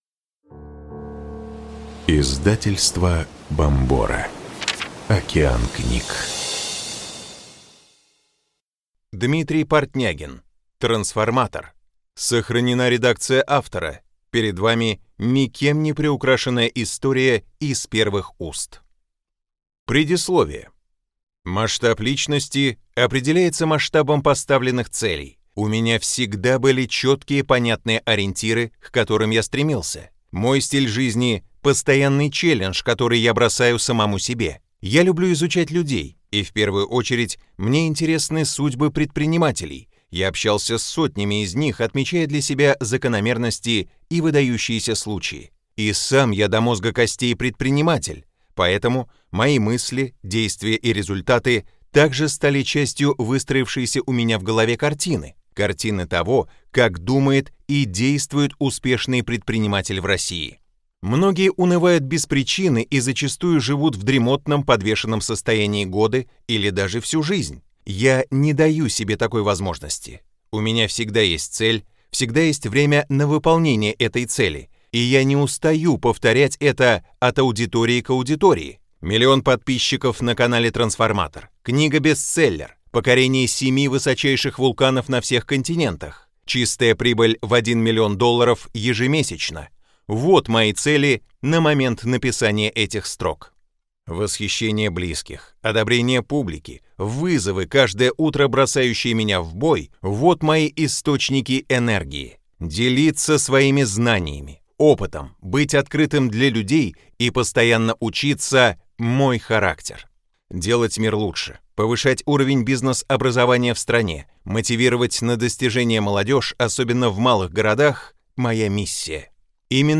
Аудиокнига Трансформатор. Как создать свой бизнес и начать зарабатывать | Библиотека аудиокниг